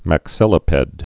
(măk-sĭlə-pĕd)